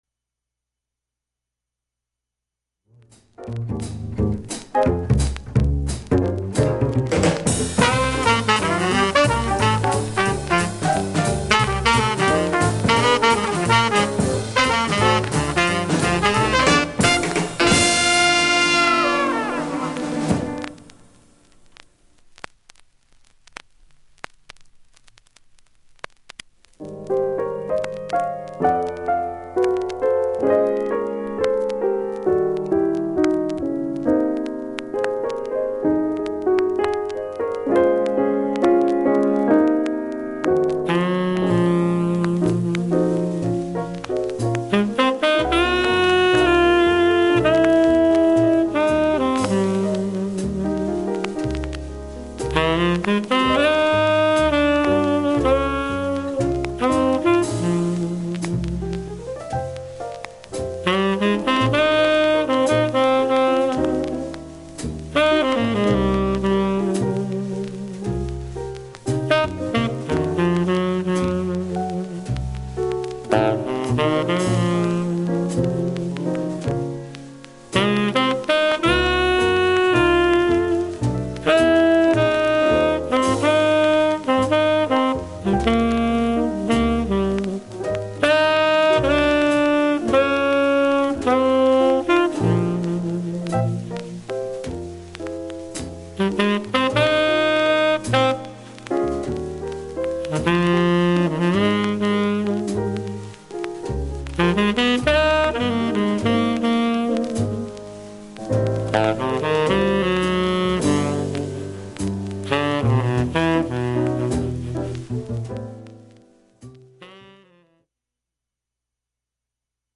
B-2始め2ミリ少し深いキズでプツ音出ます。
B-4に2センチくらいのキズでプツ出ますが、
現物の試聴（B-2前半とB-4プツ箇所すべて計6分）できます。
◆ステレオ針での試聴です。
◆ＵＳＡ盤オリジナルMONO